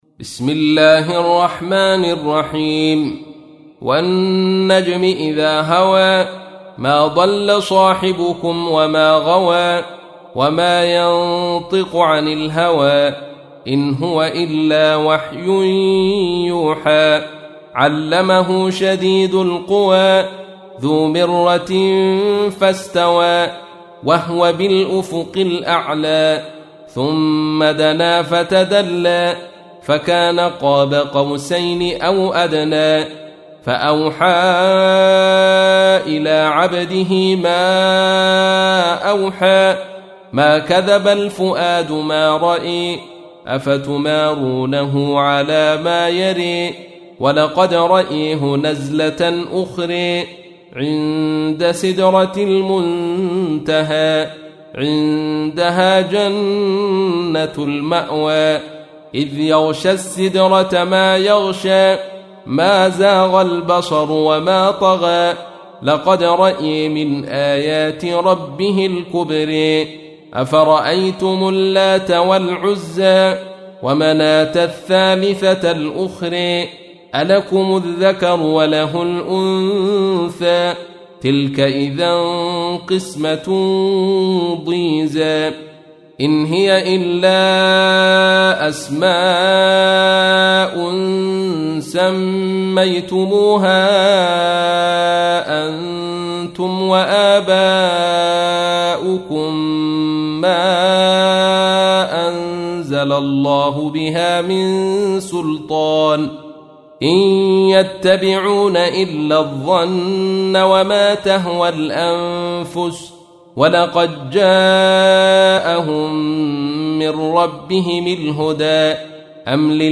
تحميل : 53. سورة النجم / القارئ عبد الرشيد صوفي / القرآن الكريم / موقع يا حسين